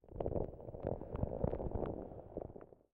Minecraft Version Minecraft Version latest Latest Release | Latest Snapshot latest / assets / minecraft / sounds / ambient / nether / nether_wastes / ground3.ogg Compare With Compare With Latest Release | Latest Snapshot